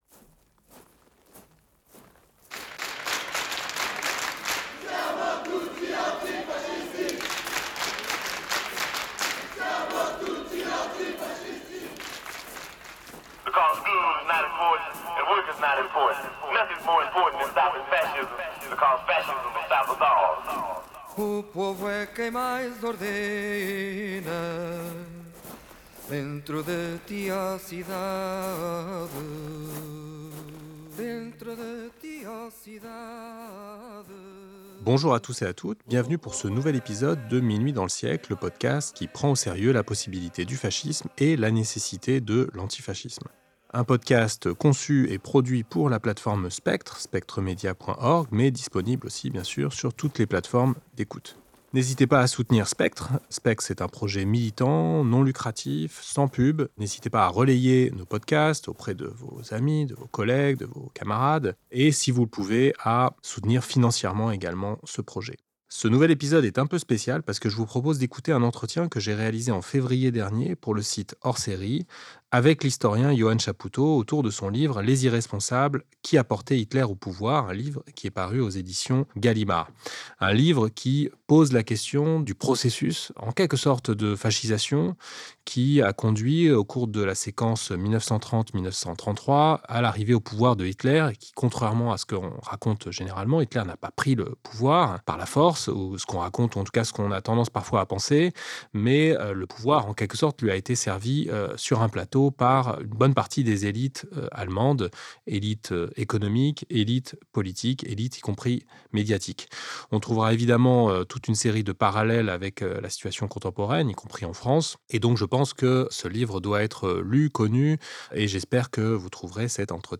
C'est cette histoire que restitue l'historien Johann Chapoutot dans son dernier livre "Les irresponsables", paru aux éditions Gallimard au début de l'année 2025. Dans cet épisode, qui reprend sous forme podcast une interview vidéo réalisée